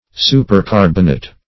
Supercarbonate \Su`per*car"bon*ate\, n. (Chem.)